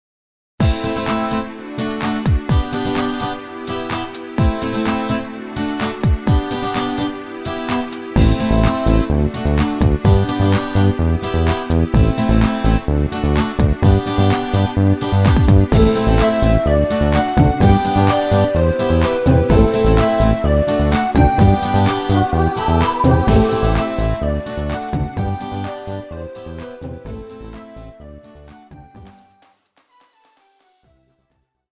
Midi a karaoke pro Vás
Žánr: Pop